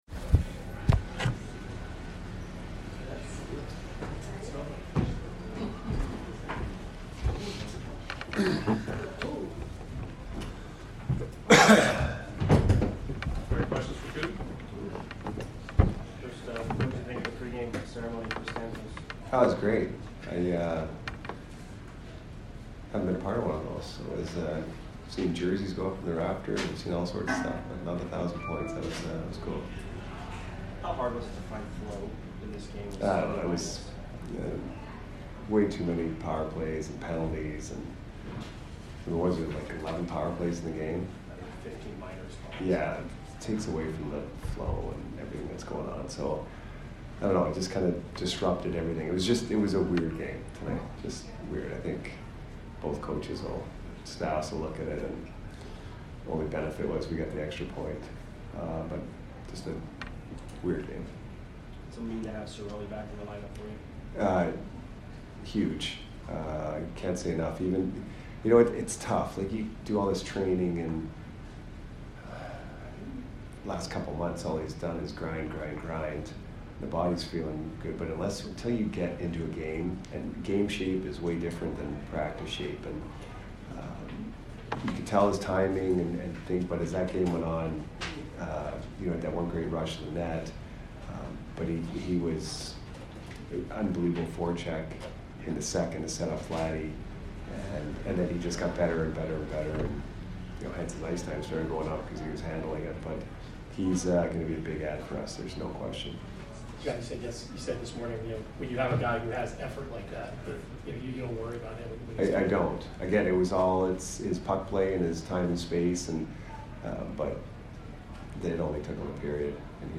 Head Coach Jon Cooper Post Game 12/3/22 vs TOR